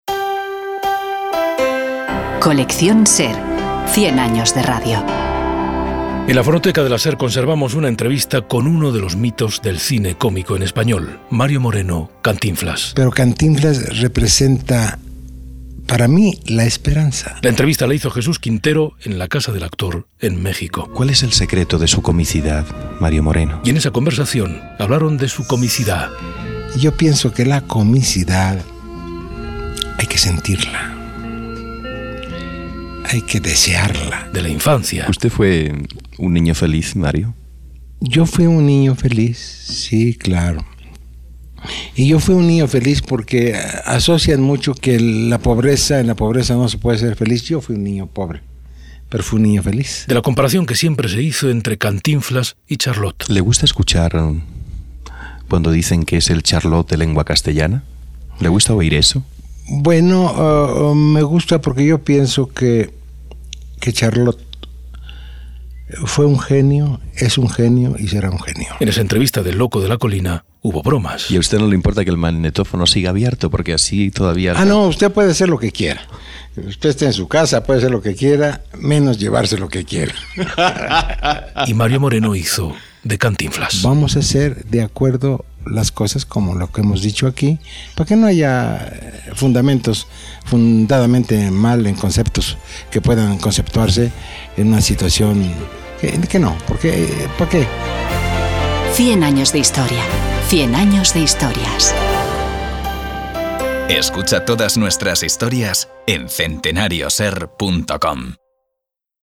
Entrevista a Cantinflas
La entrevista la hizo Jesús Quintero en la casa del actor, en México. Y en esa conversación hablaron de su comicidad, de la infancia o de la comparación que siempre se hizo entre cantinflas y Charles Chaplin.